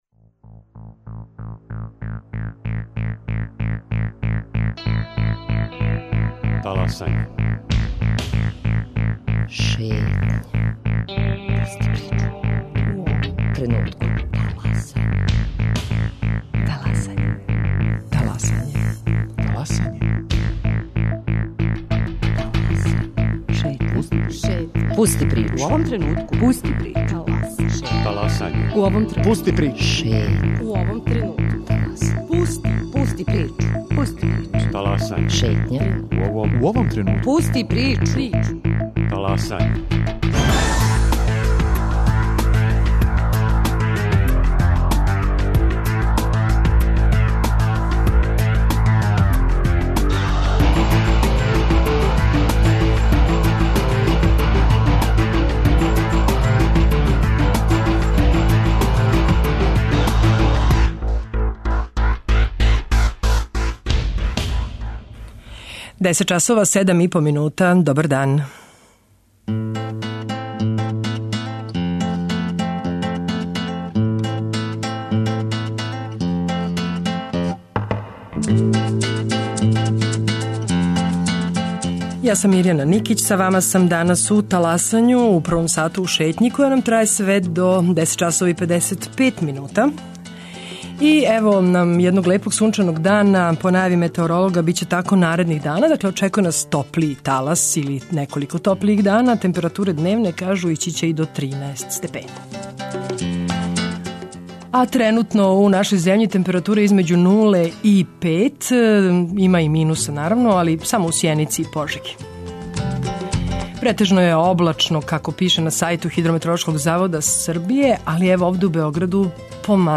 Наши гости су некадашњи ученици Математичке гимназије, сада студенти на Кембриџу.